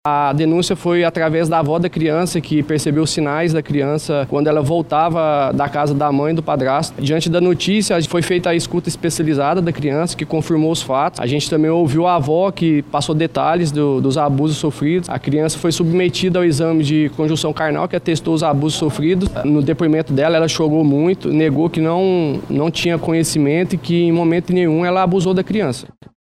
Durante a coletiva, o delegado deu detalhes sobre a prisão de uma mulher, de 27 anos, e um homem, de 25 anos, investigados por estupro de vulnerável contra uma criança de 4 anos, que é filha e enteada dos autores.